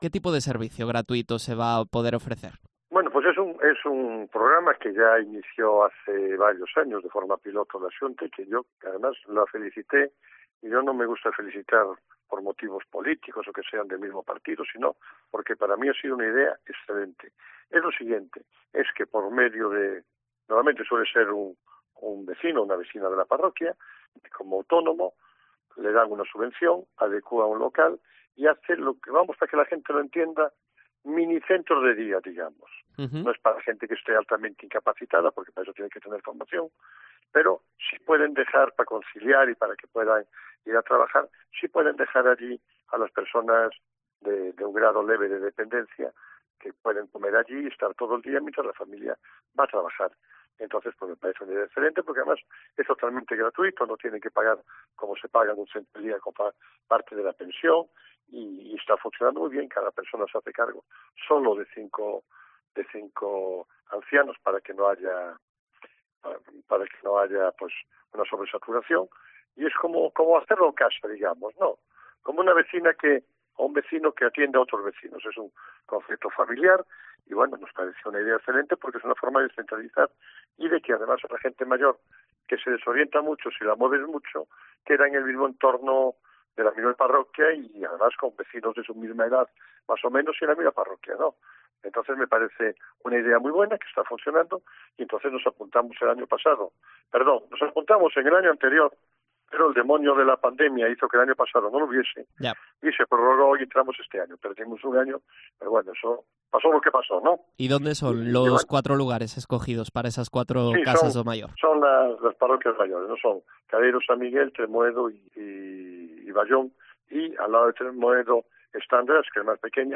Entrevista a Gonzalo Durán, alcalde de Vilanova de Arousa